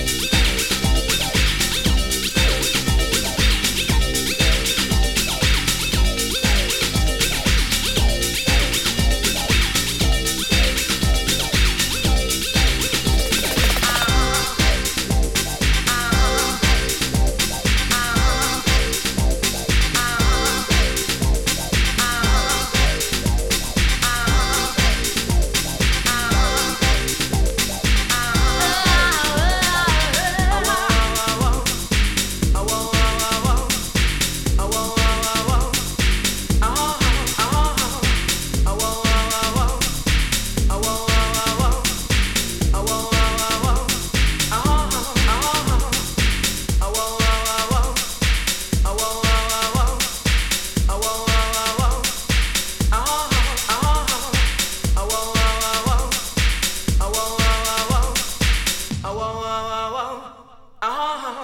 トランシー・プログレッシヴ・ハウス・チューン。